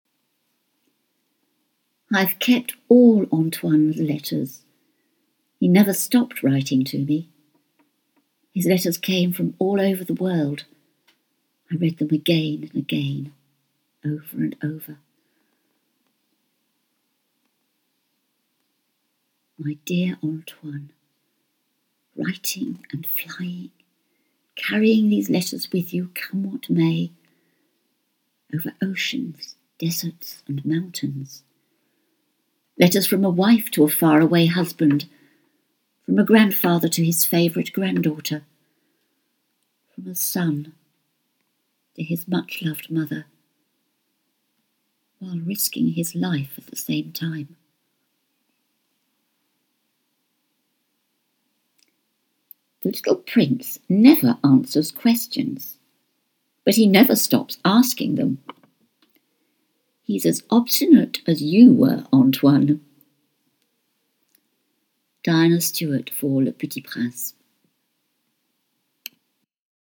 Autoguide/voix off de la maman du Petit Prince
- Soprano